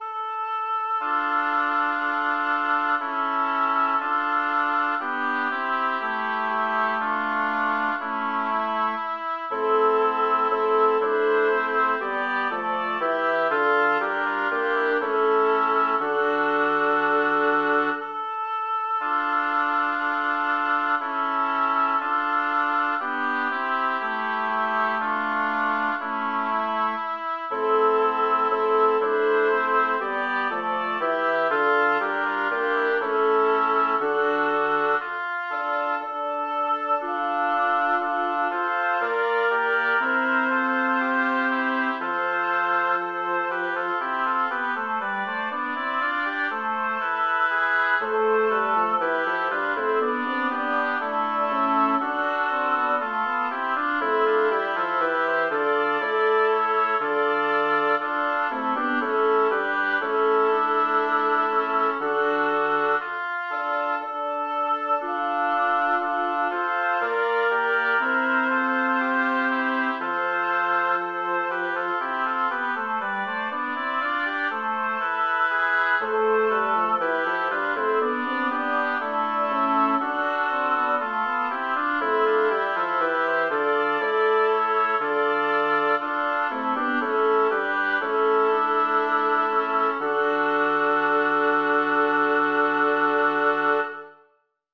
Title: Mentr'io vissi in dolore Composer: Orazio Vecchi Lyricist: Number of voices: 4vv Voicing: SSSA Genre: Secular, Canzonetta
Language: Italian Instruments: A cappella